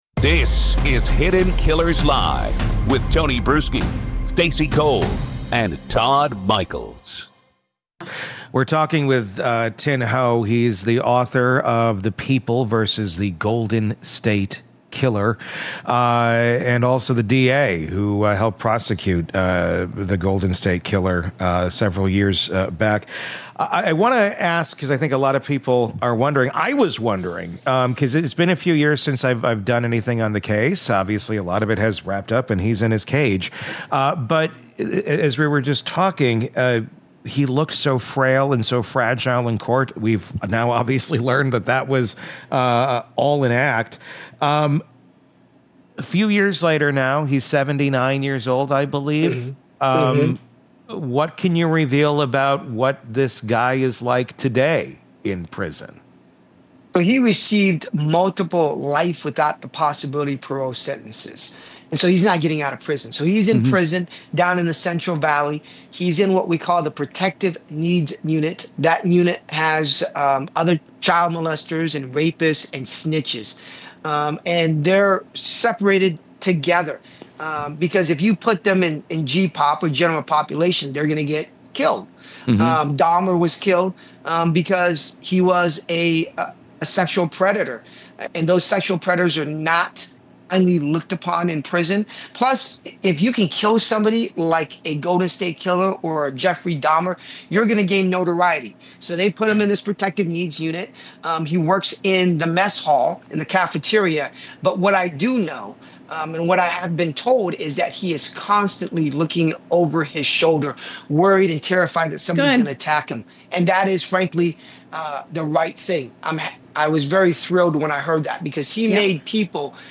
In this exclusive conversation